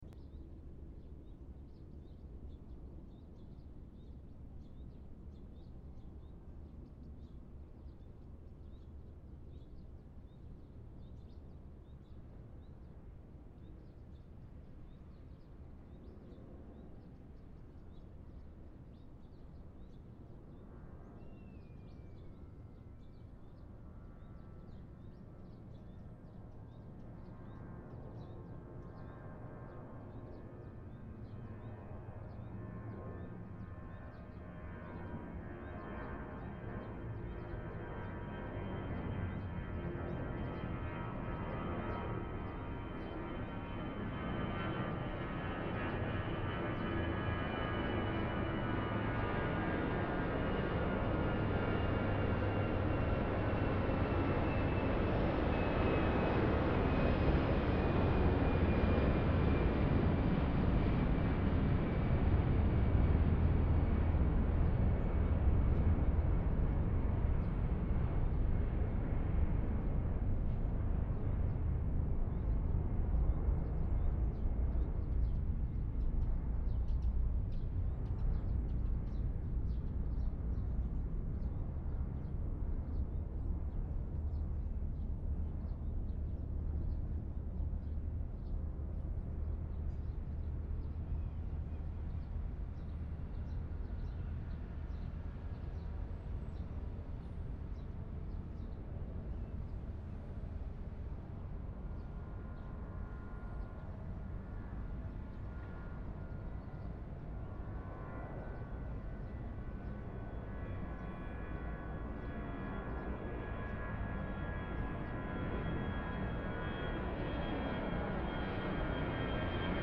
Auralization of atmospheric turbulence-induced amplitude fluctuations in aircraft flyover sound based on a semi-empirical model | Acta Acustica
01_measurement_low_turbulence.mp3 (6